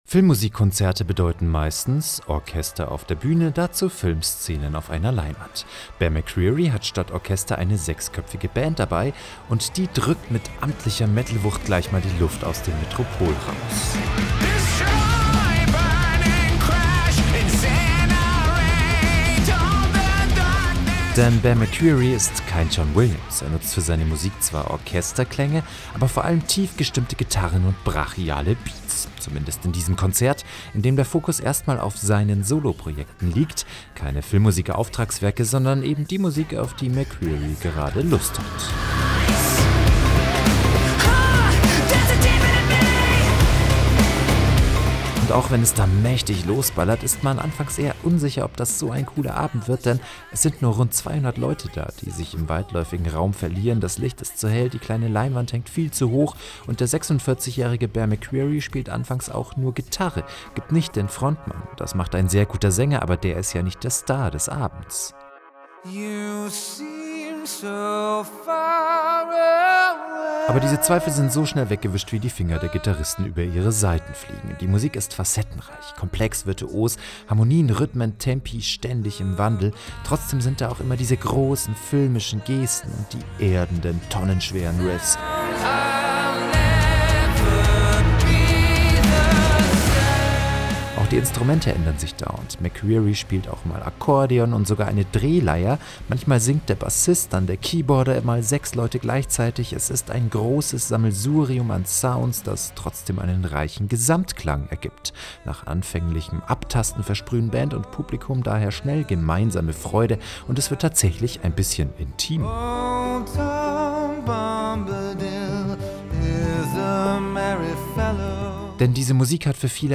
Der Wegweiser durch die weite Kulturlandschaft von Berlin und Brandenburg: Premieren- und Konzertkritiken, Film- und Musiktipps, Rundgänge durch aktuelle Ausstellungen.